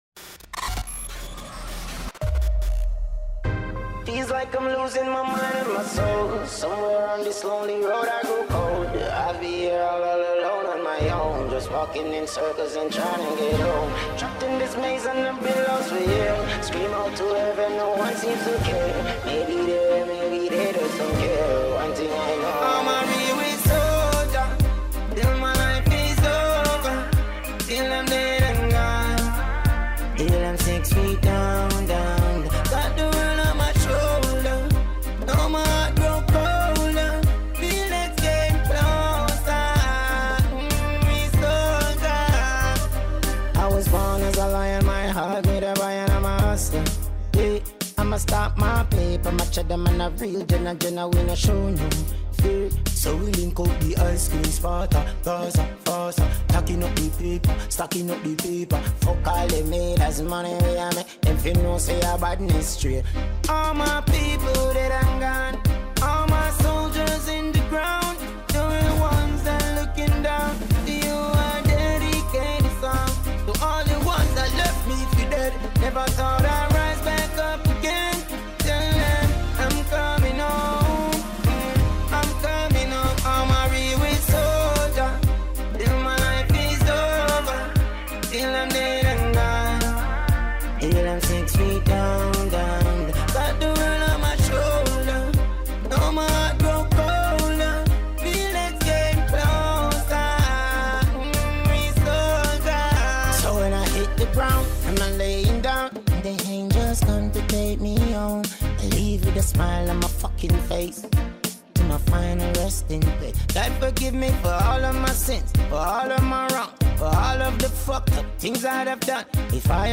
Top leading Jamaican dancehall king